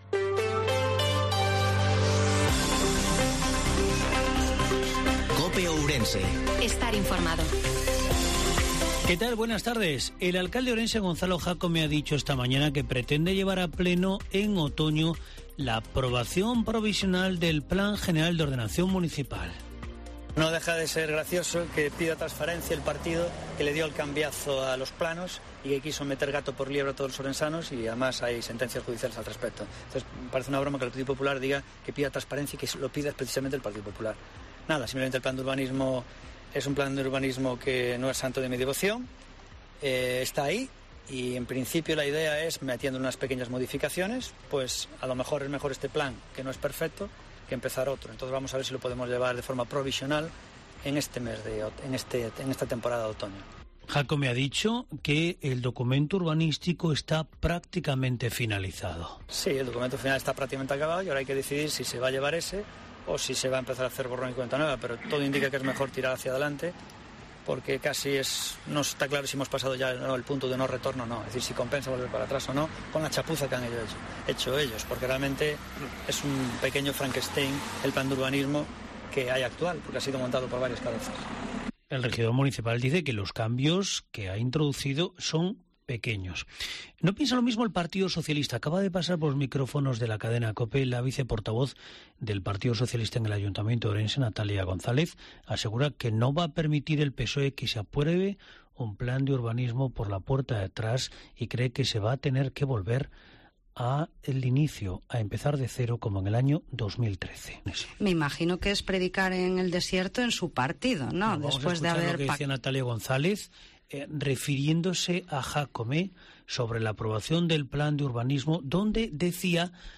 INFORMATIVO MEDIODIA COPE OURENSE-15/09/2022